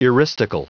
Prononciation du mot eristical en anglais (fichier audio)
Prononciation du mot : eristical